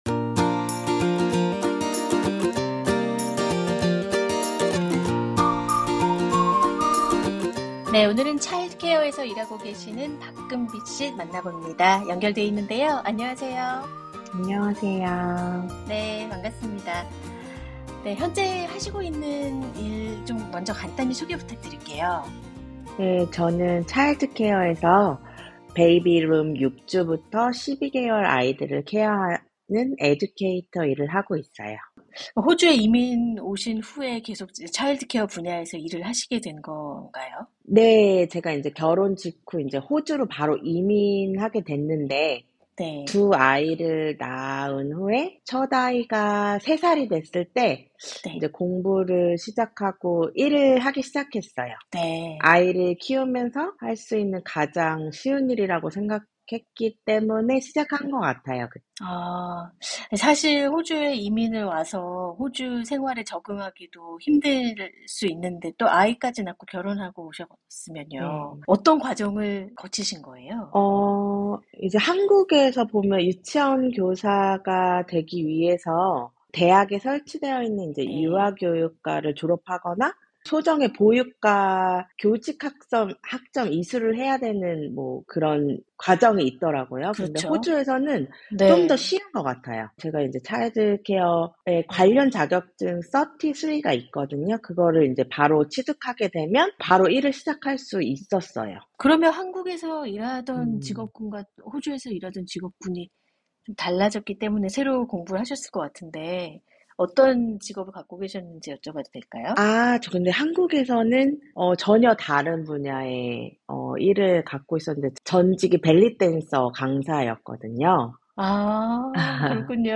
Job 인터뷰